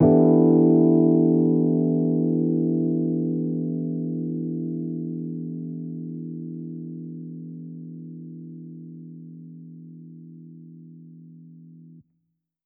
JK_ElPiano2_Chord-Em11.wav